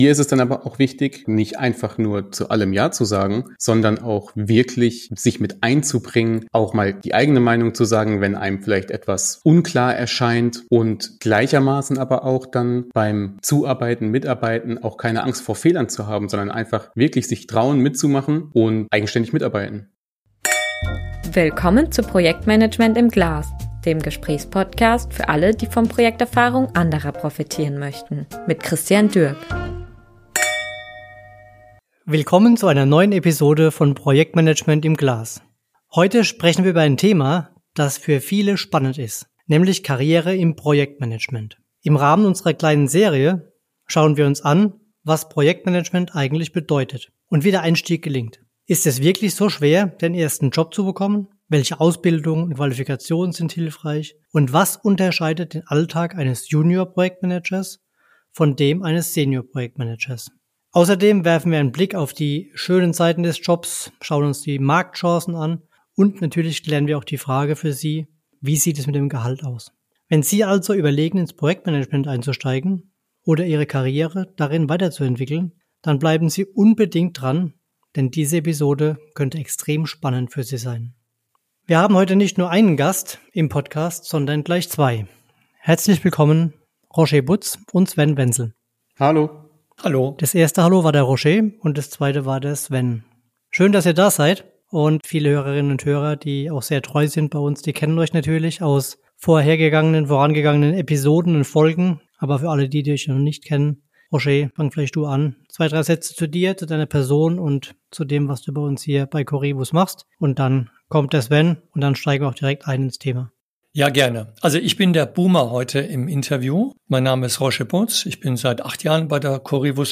#78 Karriere im Projektmanagement – Ein Gespräch zwischen Erfahrung und Aufbruch ~ Projektmanagement im Glas Podcast